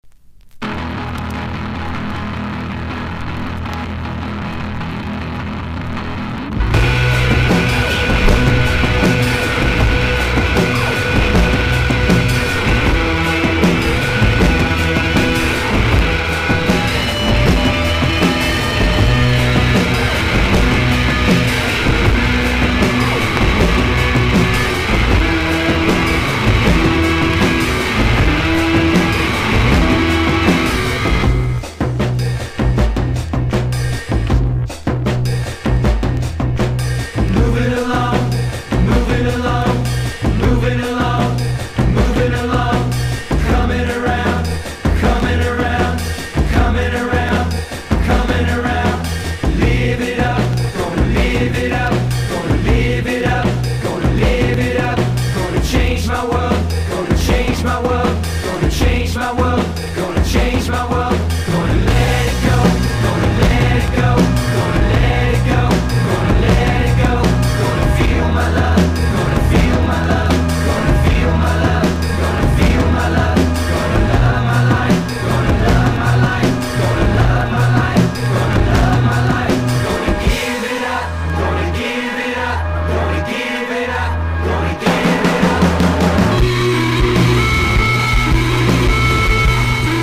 SHOEGAZER / CHILLWAVE / DREAM POP